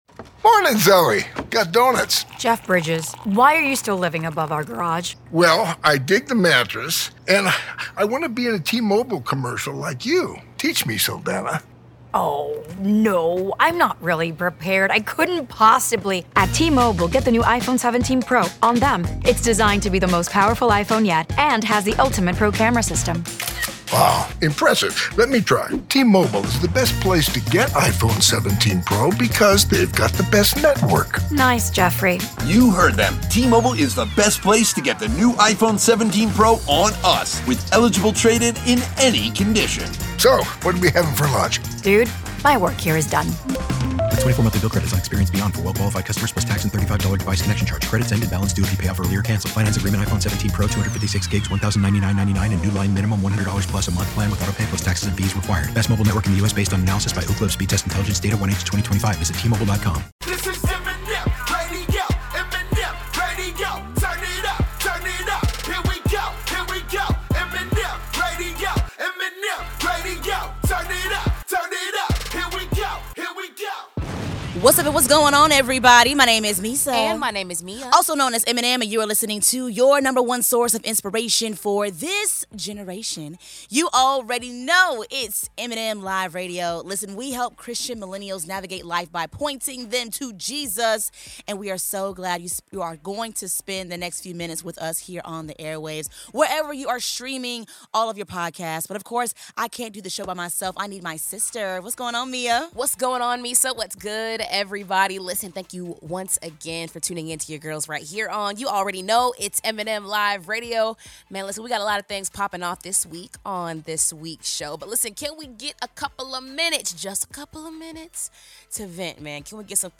Through inspiring music, powerful stories, and thought-provoking interviews, this is the show where faith meets culture—all to point you back to Jesus.